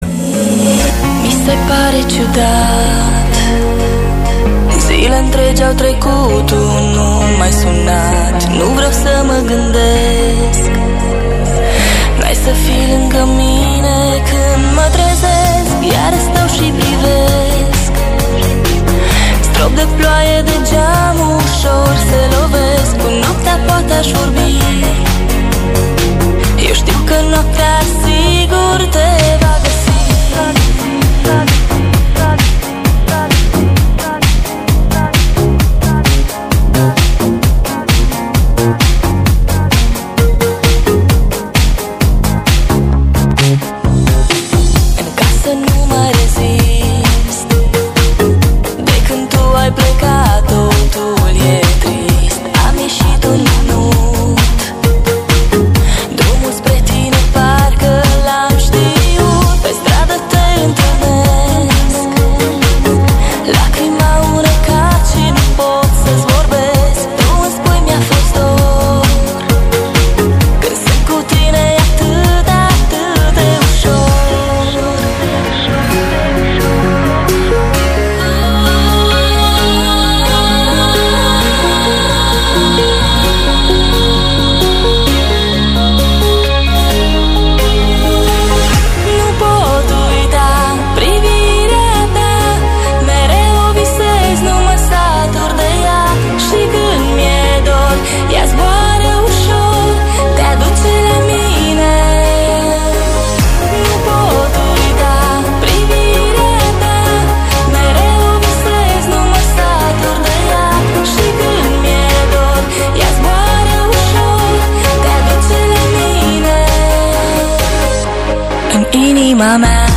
Categoria: Pop